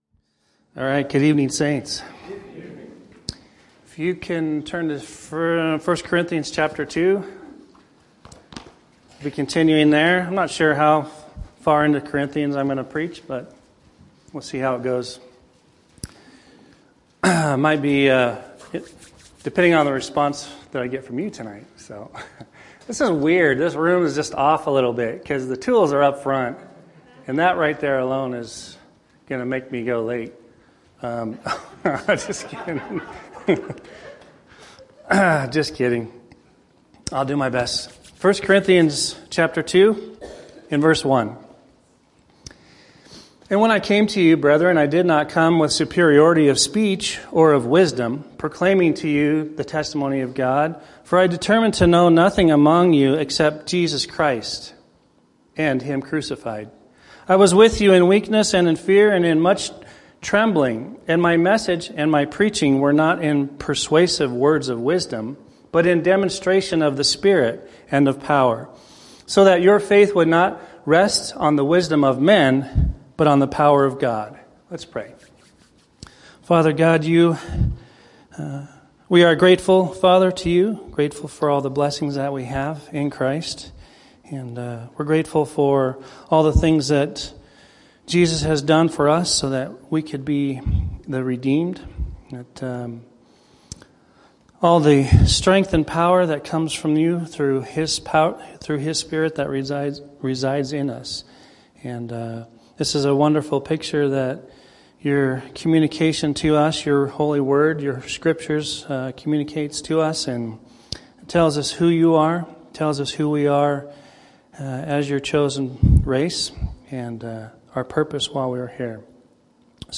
Series: Evening Messages Tagged with shedding of blood , guilt , persuade , logic , cross , deeds , reconciled through Christ , Apostle Paul , Christ crucified , love , demonstration , gospel